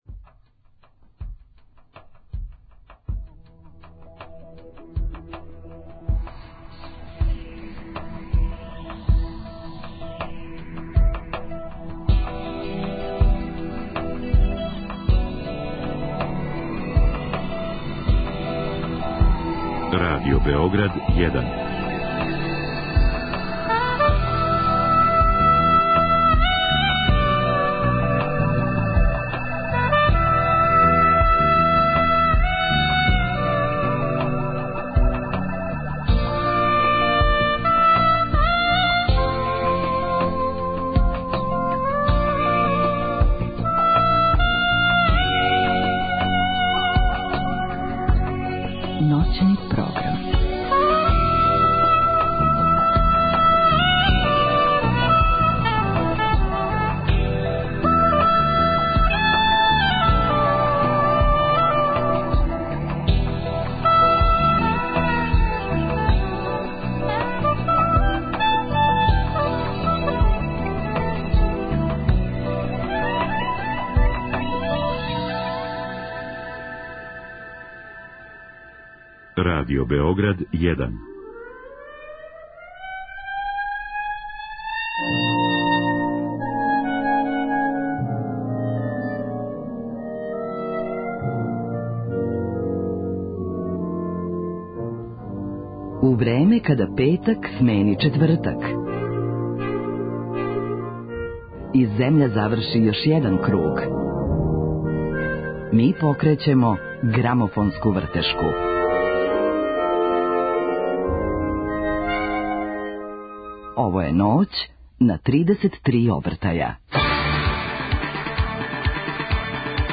Испод јелке су наравно плоче, које ће уместо камина пуцкетати за нас.